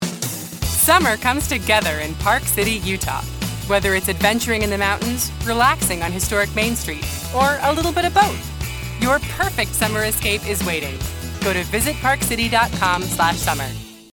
Utah Tourism TV Ad VO Demo
My voice is warm and comforting, relatable, humorous, and authoritative. As a natural alto I am very comfortable in low, smokey and intimate ranges.